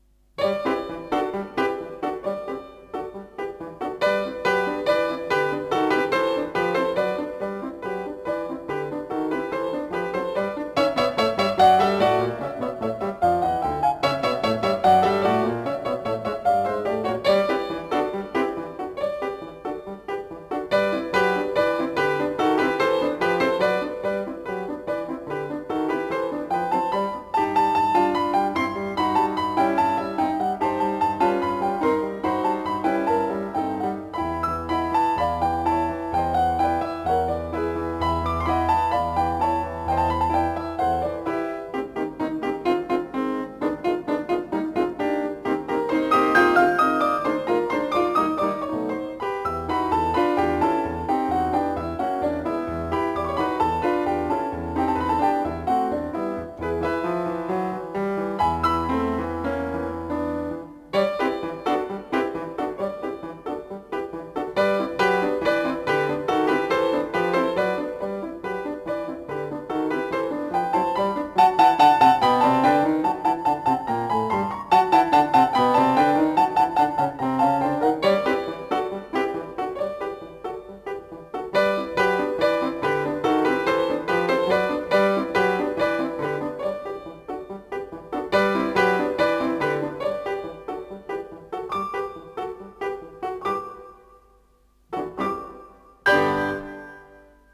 дитяча п'єса для фортепіано в чотири руки